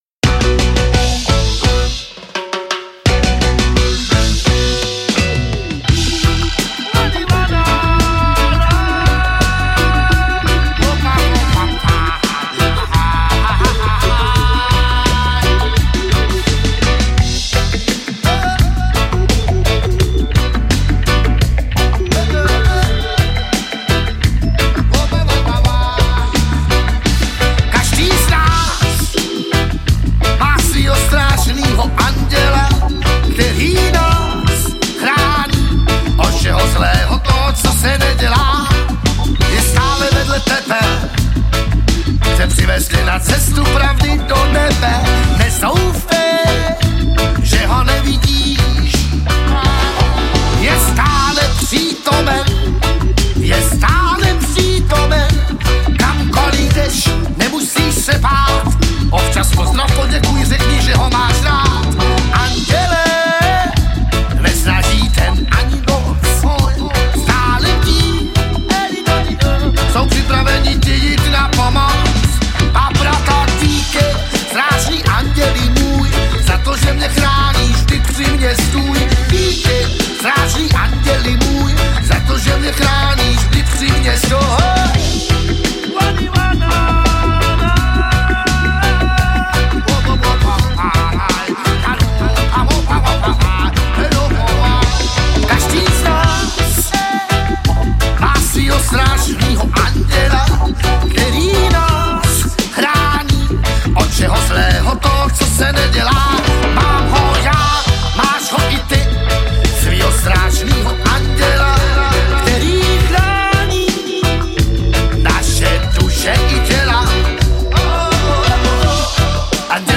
Žánr: Ska/Funk/Reggae
Doprovází se přitom na varhany Hammond.